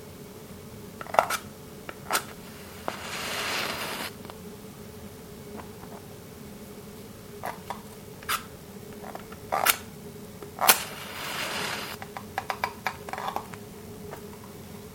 Frottements / craquements d'allumettes